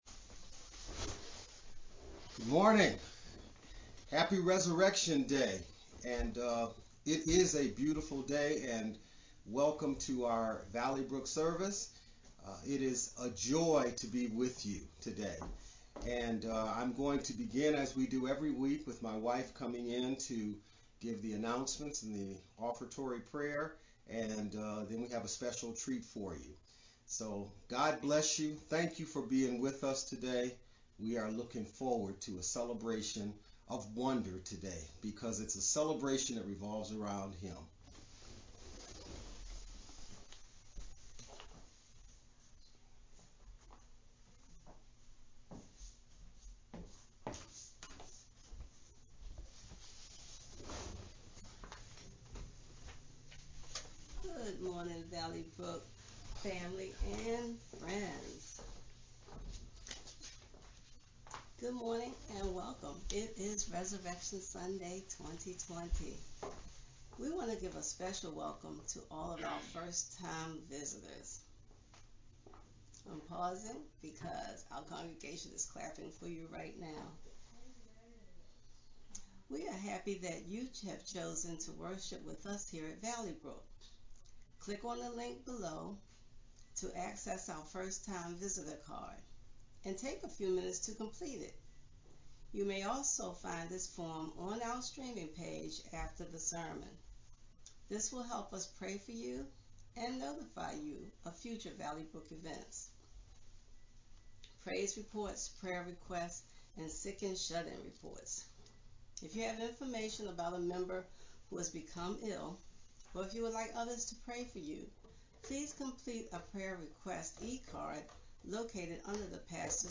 Easter-service-cut-mp3-CD.mp3